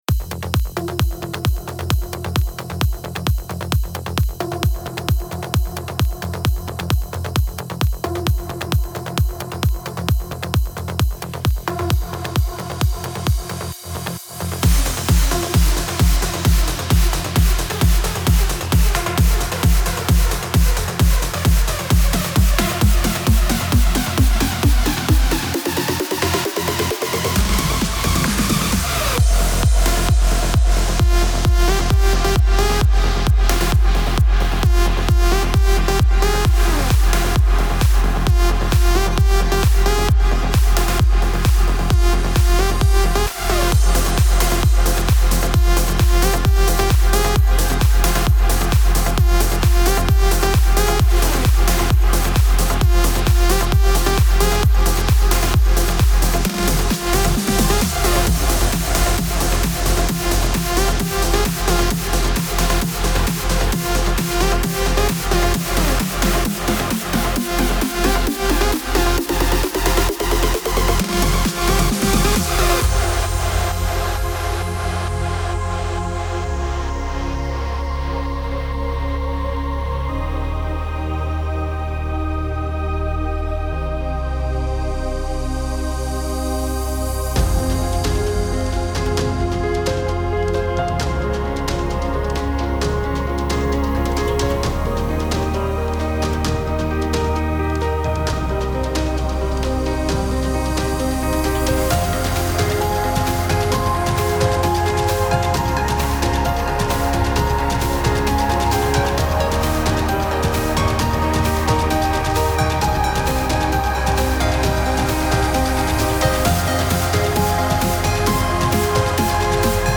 امید‌بخش , پر‌انرژی , ترنس , موسیقی بی کلام